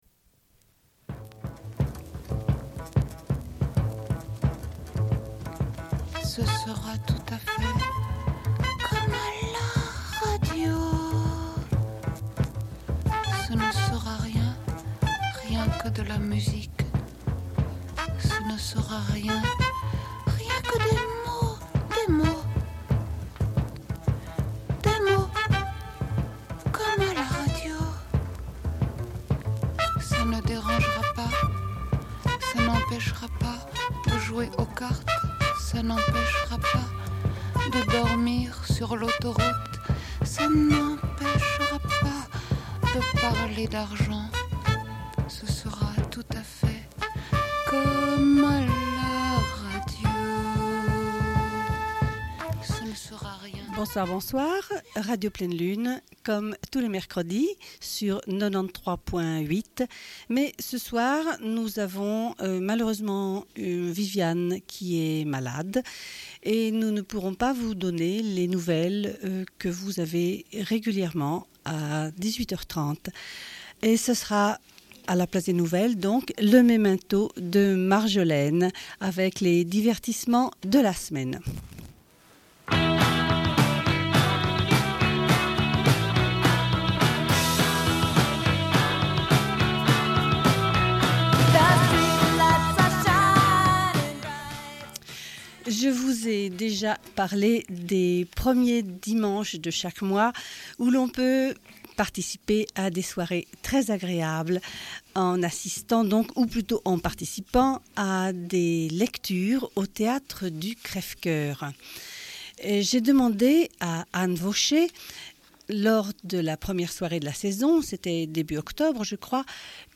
Bulletin d'information de Radio Pleine Lune du 21.10.1992 - Archives contestataires
Une cassette audio, face B29:29